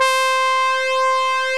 BRASS2-HI.wav